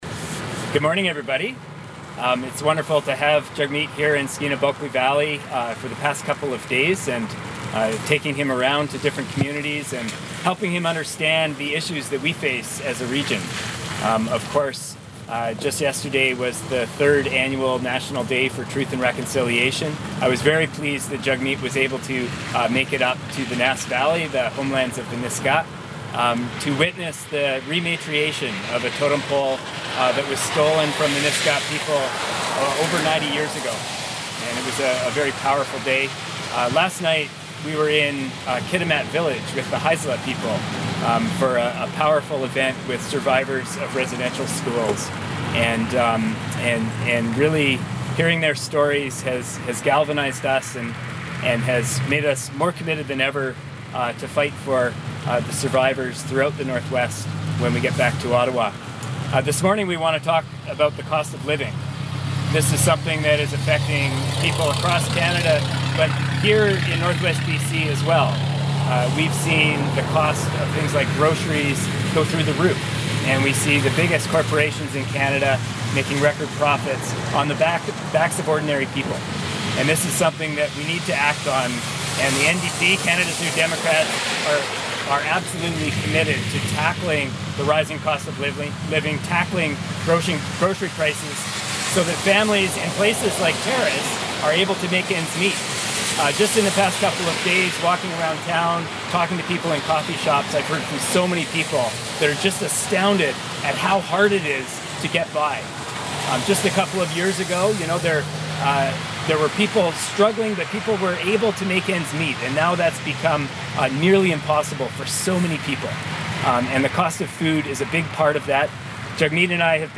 Terrace served as the backdrop this past weekend as federal NDP leader Jagmeet Singh announced his plans to deal with rising grocery costs.
Listen below to Skeena-Bulkley Valley MP Taylor Bachrach introduce NDP leader Jagmeet Singh, followed by Singh's comments.